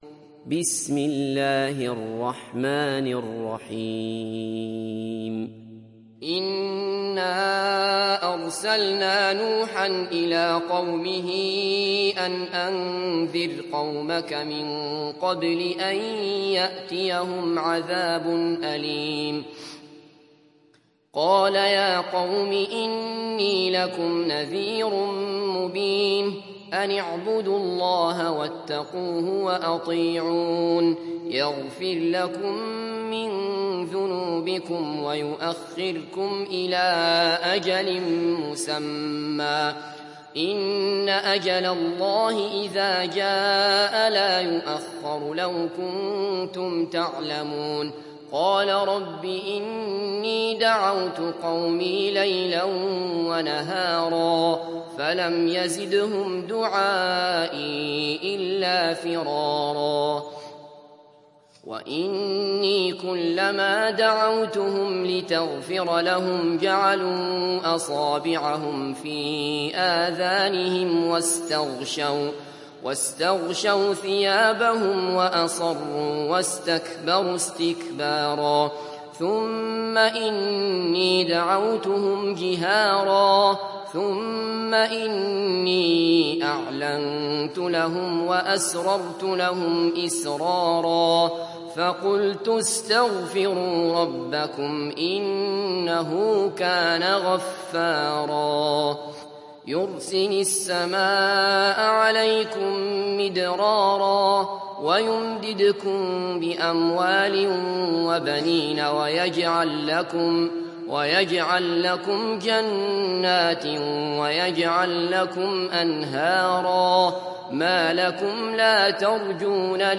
Surah Nuh Download mp3 Abdullah Basfar Riwayat Hafs from Asim, Download Quran and listen mp3 full direct links